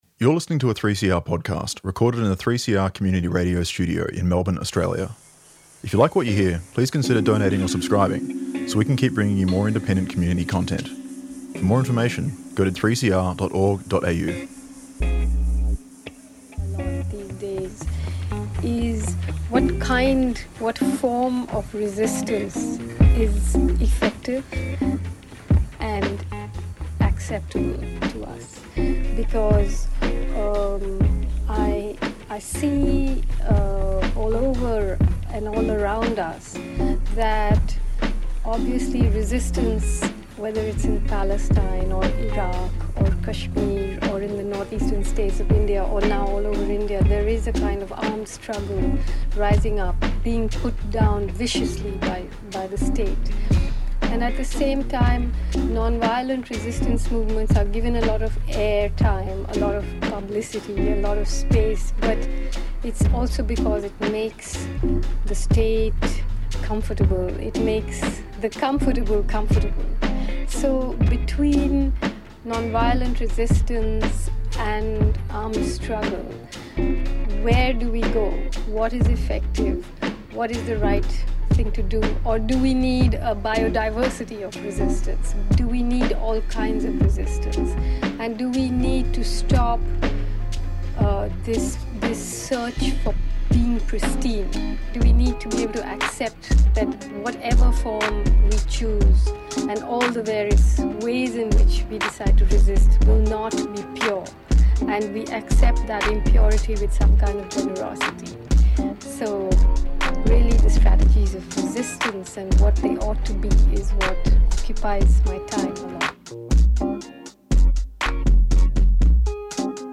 Discussion from the Naarm launch of Black thoughts matter: Essays on Black love, Black power, and Black joy, hosted by the Institute of Postcolonial Studies in North Melbourne on Friday 14 November 2025.